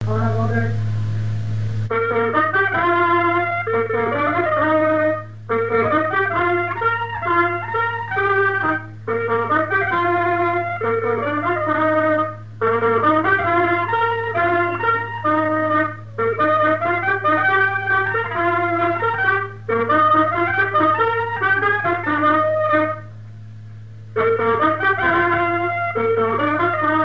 Lairoux ( Plus d'informations sur Wikipedia ) Vendée
danse : quadrille : avant-deux
Trois avant-deux au piano, et un quadrille aux cuivres
Pièce musicale inédite